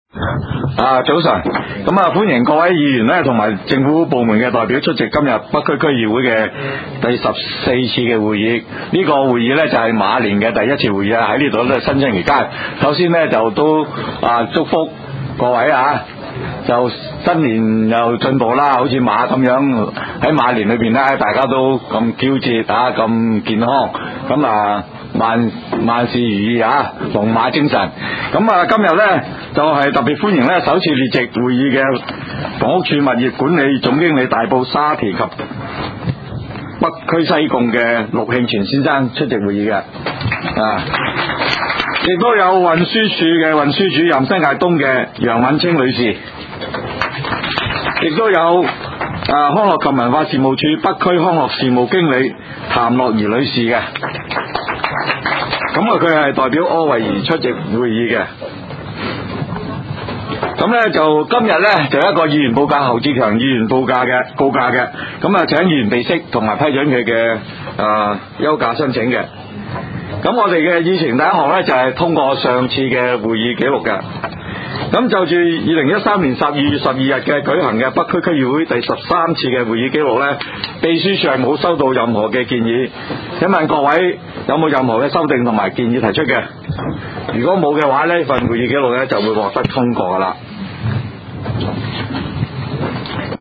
区议会大会的录音记录
北区区议会会议室